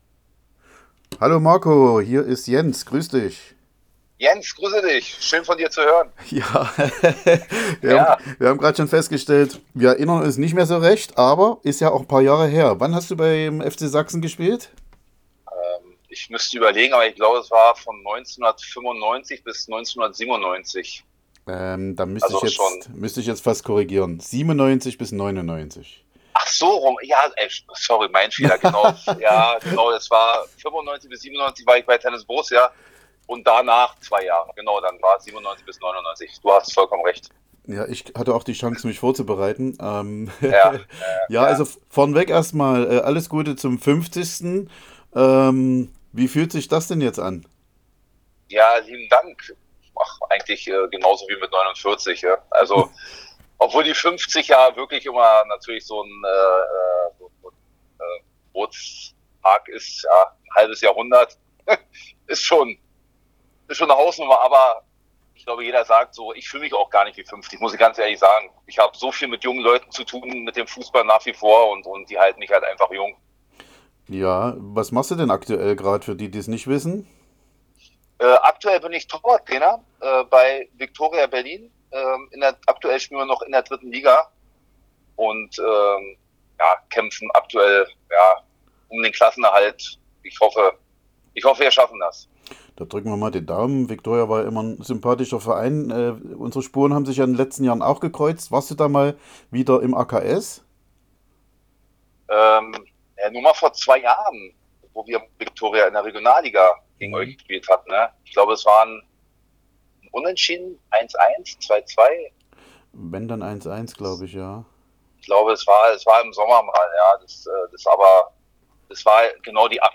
Das gesamte Gespräch gibt es hier.